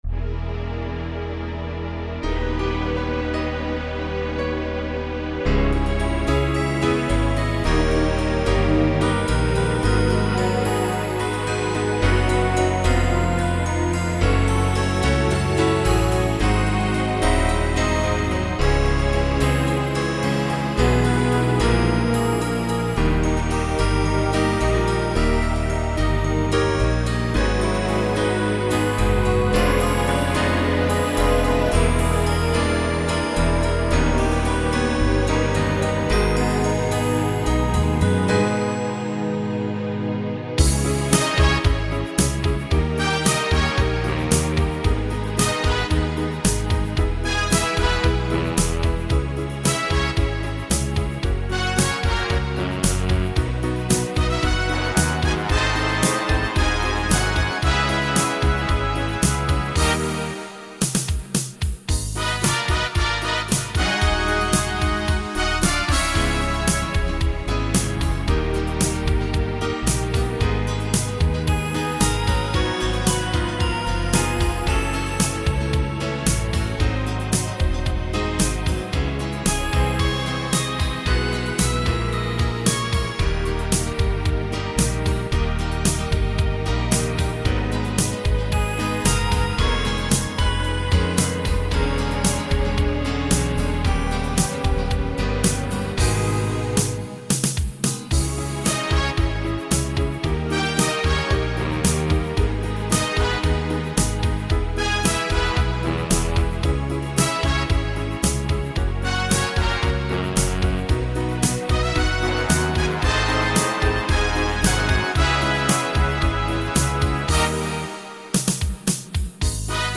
минусовка версия 46084